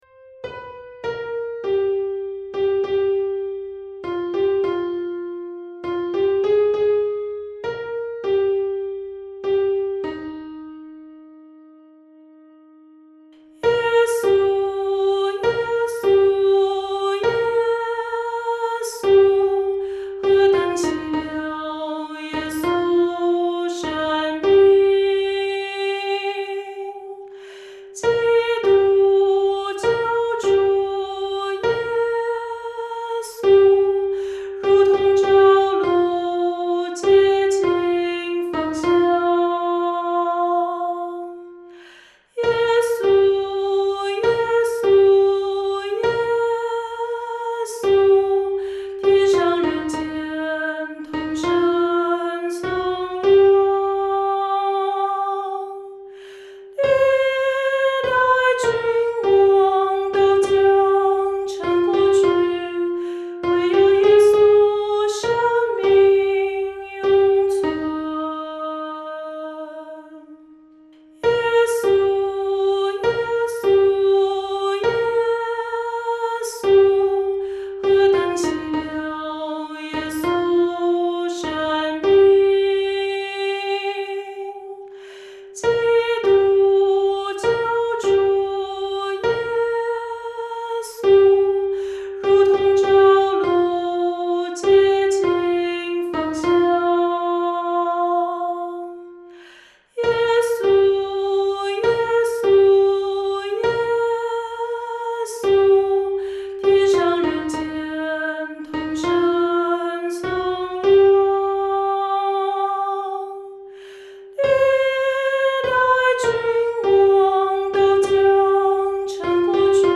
女高